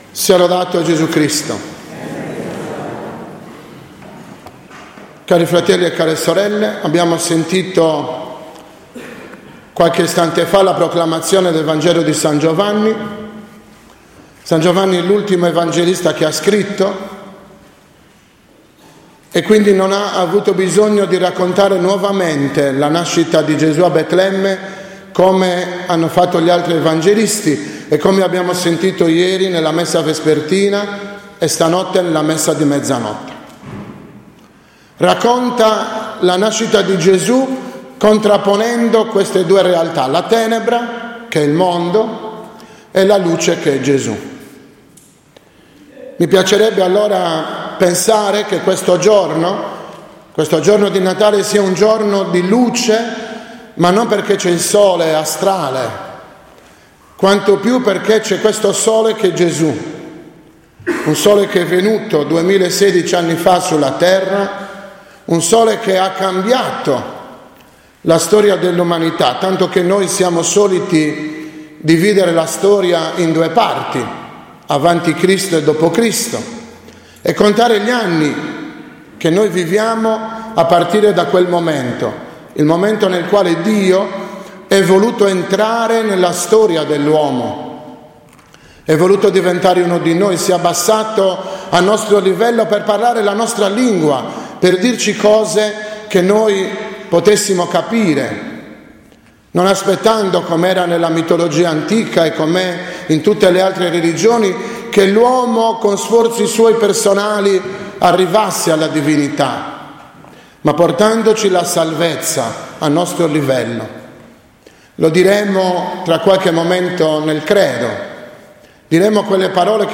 25.12.2016 – OMELIA DELLA S. MESSA DEL GIORNO NEL NATALE DEL SIGNORE (ORE 10)